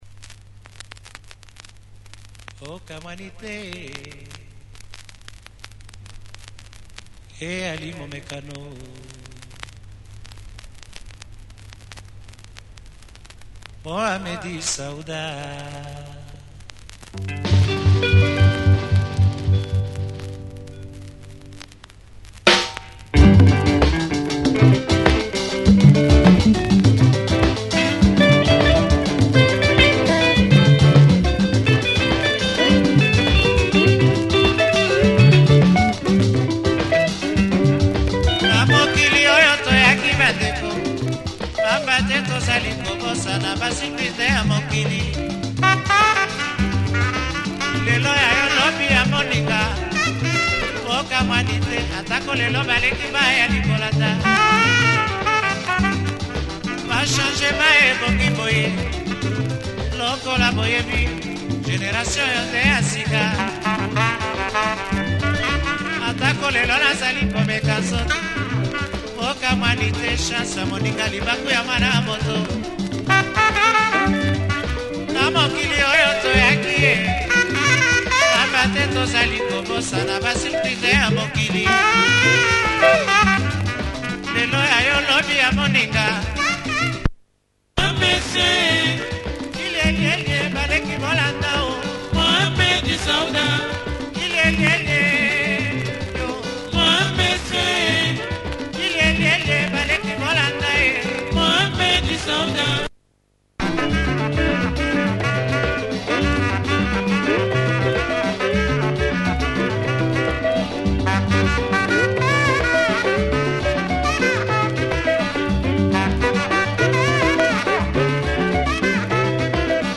Lovely vocals and a great vibe throughout the track.